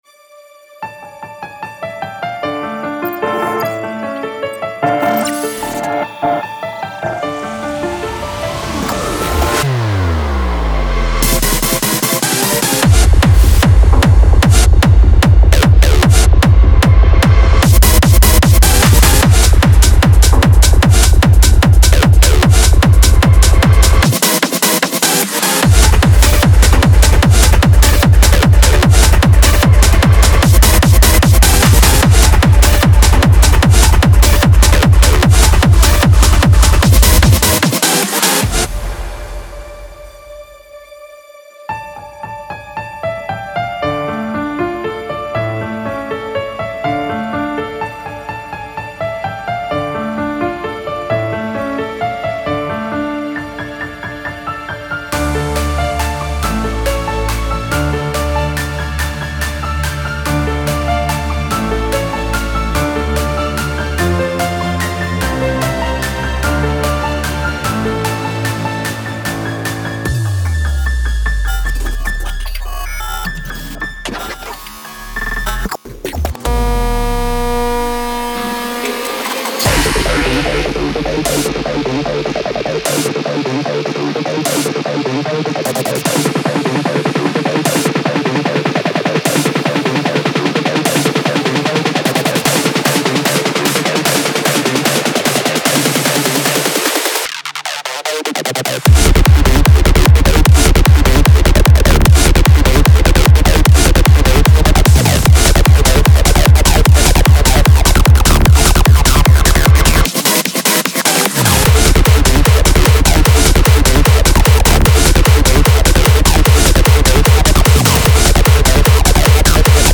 • Жанр: Hardstyle, Dance